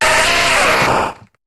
Cri de Racaillou dans Pokémon HOME.